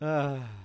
笑声 " 笑声11
描述：小声的笑声。
Tag: 笑声 声音